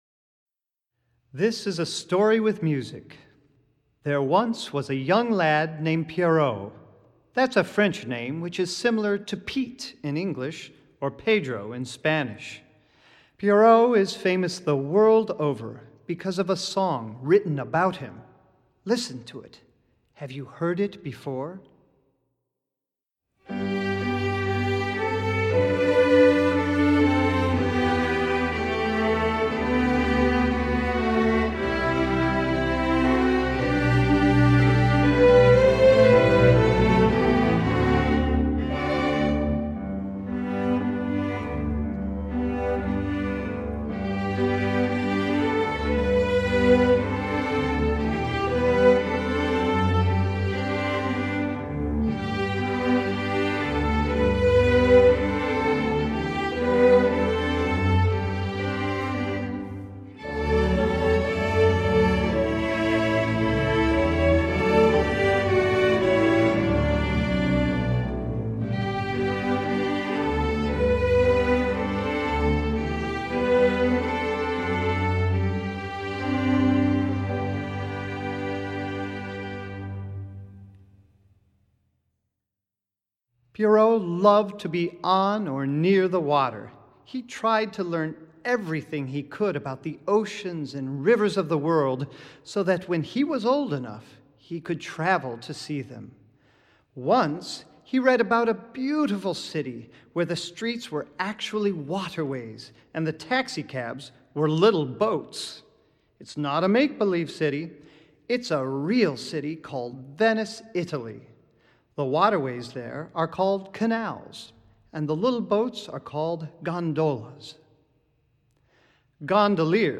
Voicing: String Orchestra W